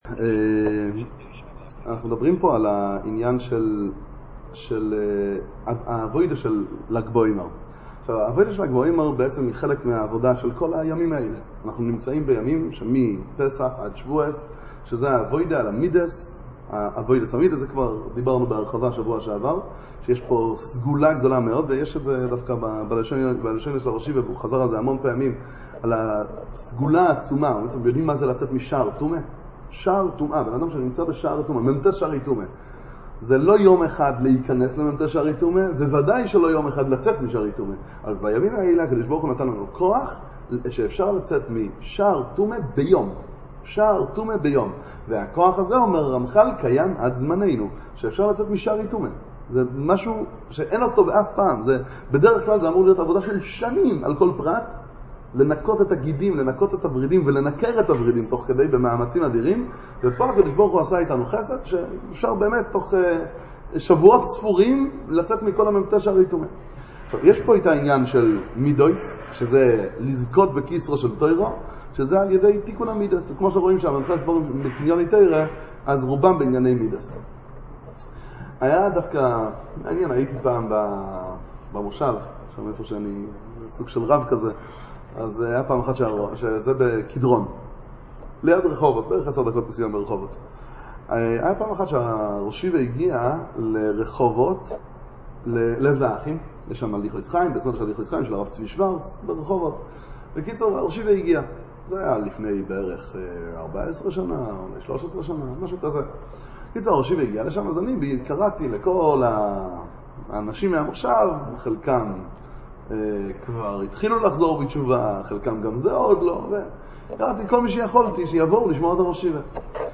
דבר תורה ל"ג בעומר, שיעור על גדולת רשב"י, שיעורי תורה בענין רבי שמעון בר יוחאי